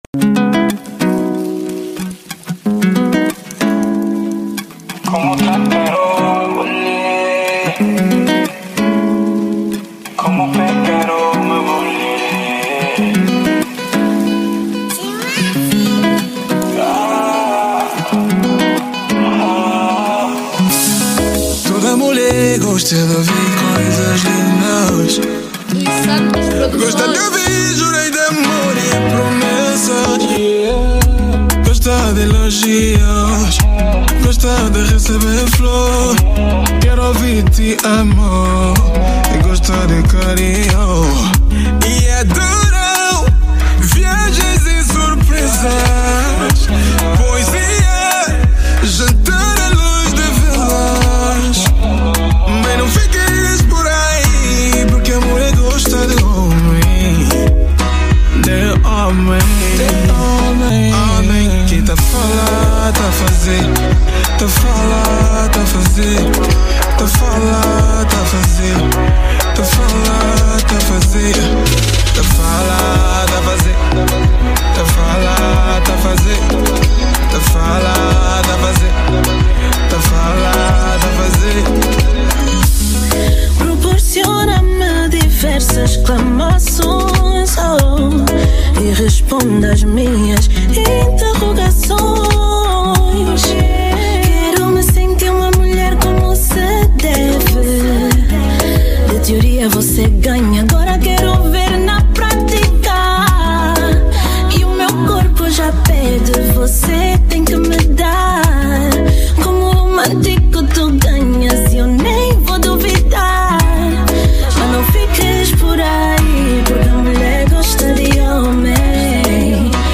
Género: Afro House